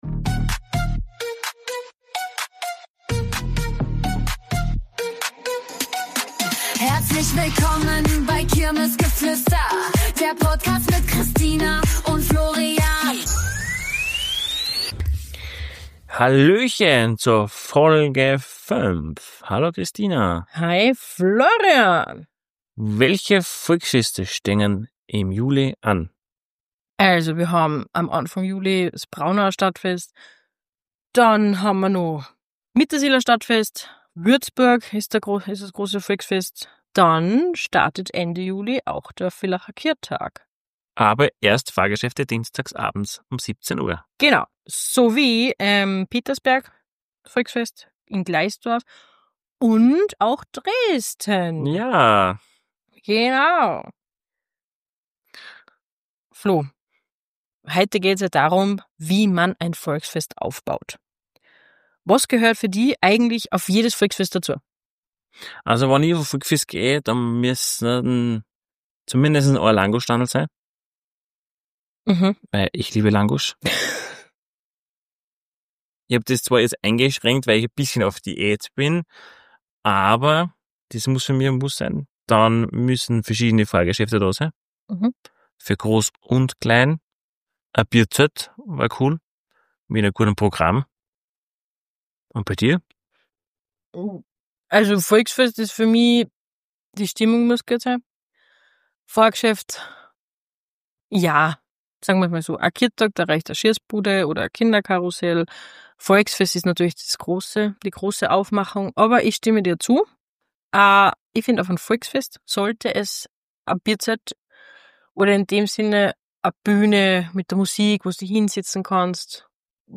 In dieser Folge erklären wir euch wie Schausteller ein Volksfest gestalten würden. Auch ein Interview ist wieder mit dabei.